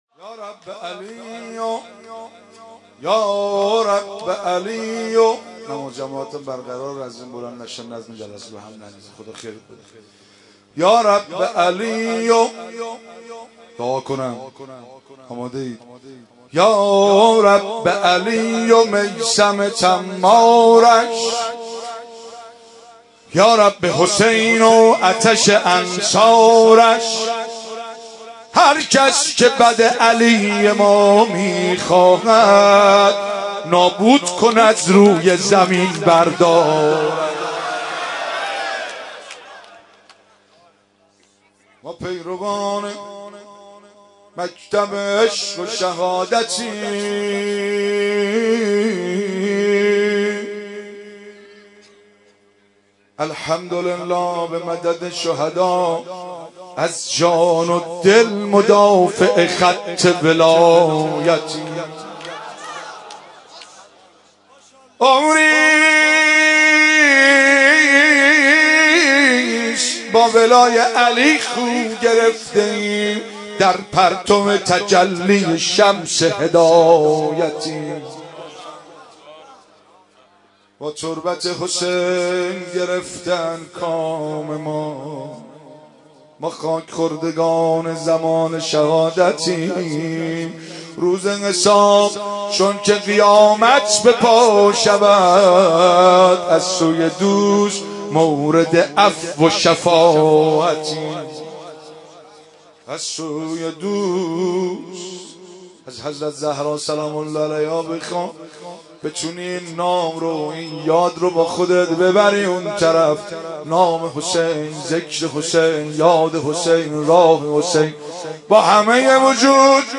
مناسبت : شهادت حضرت فاطمه زهرا سلام‌الله‌علیها
قالب : مناجات